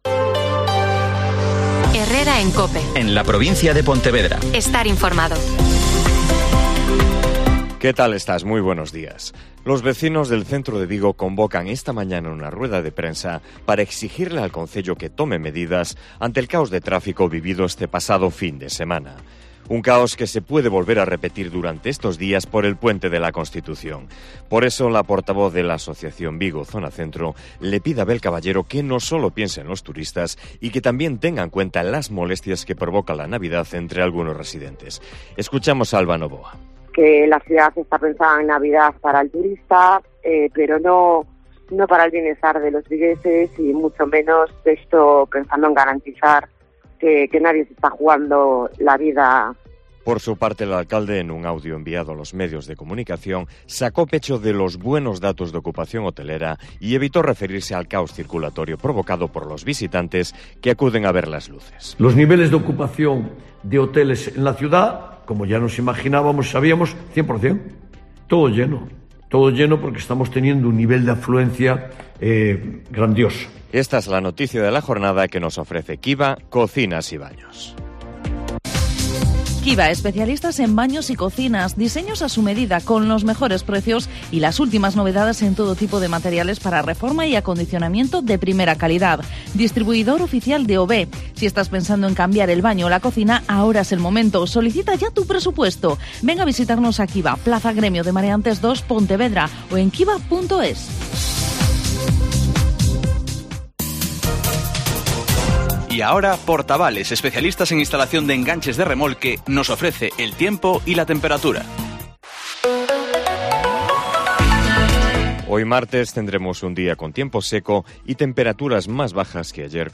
Herrera en COPE Pontevedra y COPE Ría de Arosa (Informativo 08:24h)